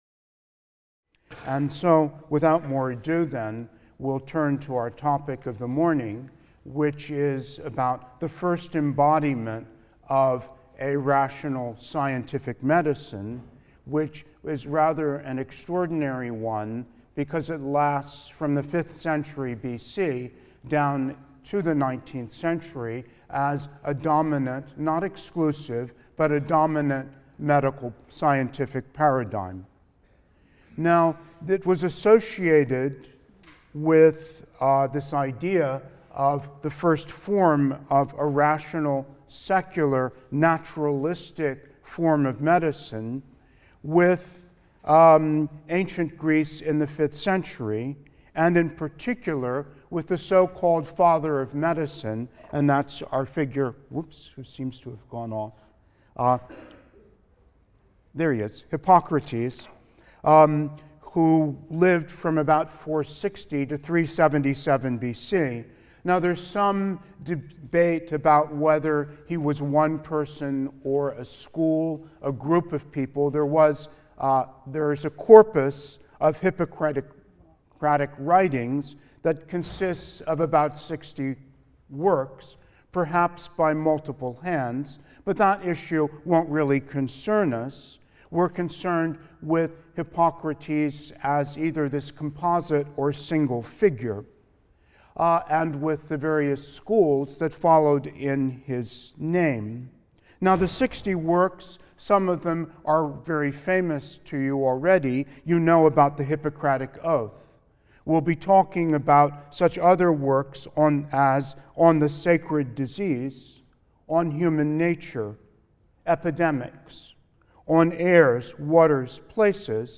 HIST 234 - Lecture 2 - Classical Views of Disease: Hippocrates, Galen, and Humoralism | Open Yale Courses